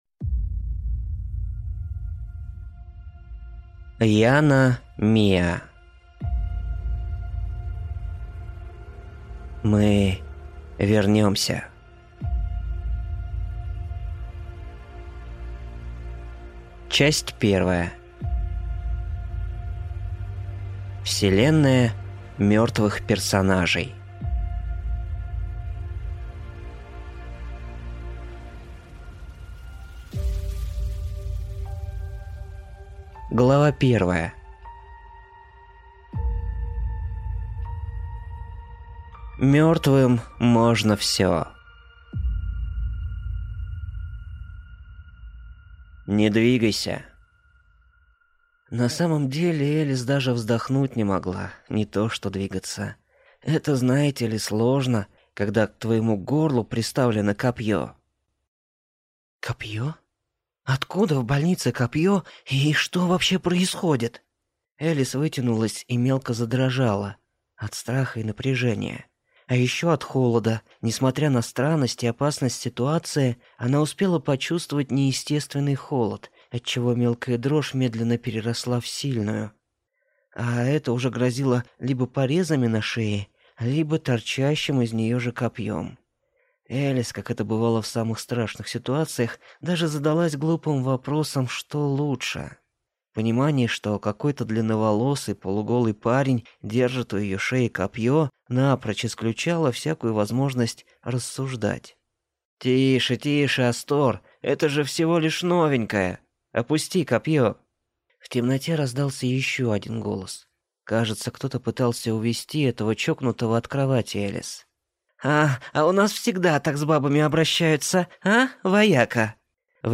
Аудиокнига Мы вернемся | Библиотека аудиокниг